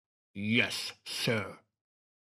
Вы найдете разные варианты: от радостного и энергичного до томного и шепотного «yes», записанные мужскими, женскими и детскими голосами.